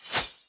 Sword Attack 1.wav